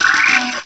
cry_not_ferroseed.aif